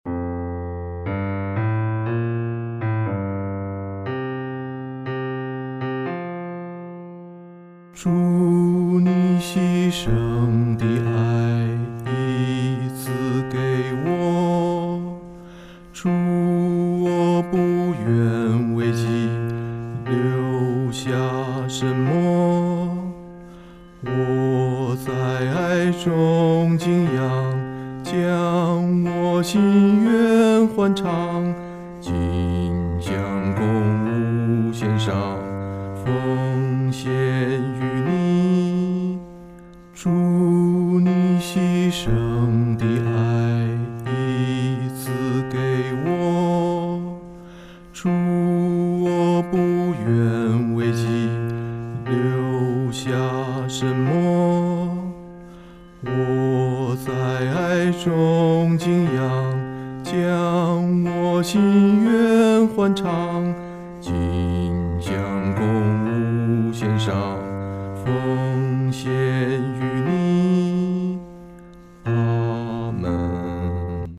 合唱
男低